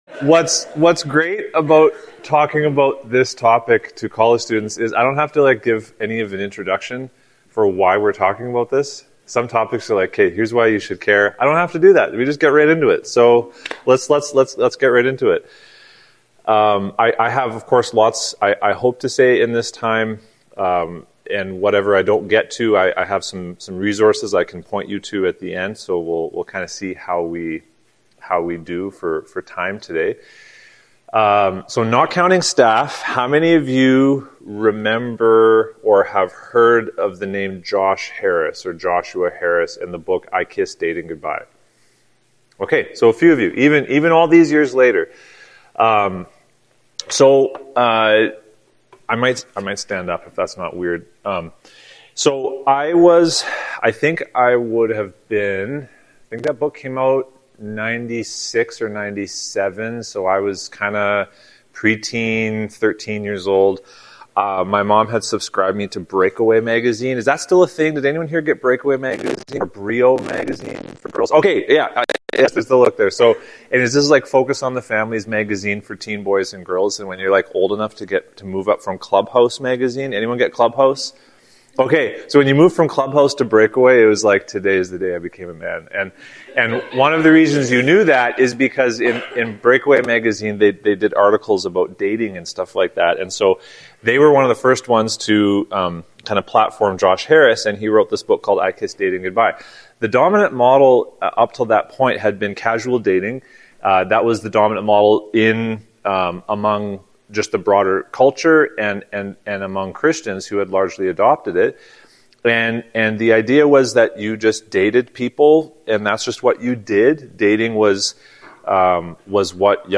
Emmanuel Baptist Church of Nipawin Sermons